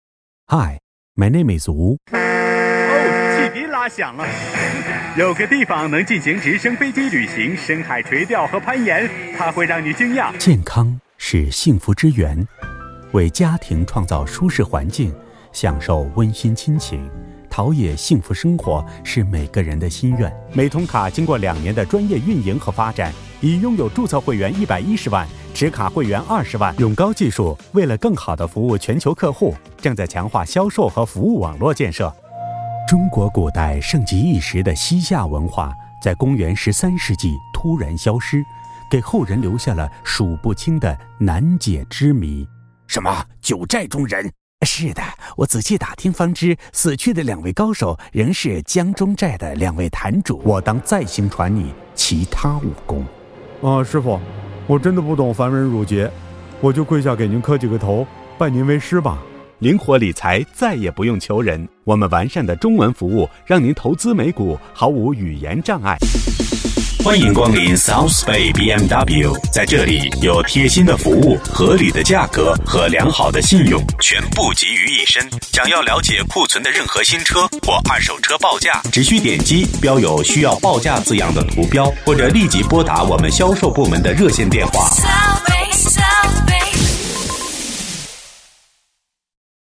Accent-Free Chinese Voice Talent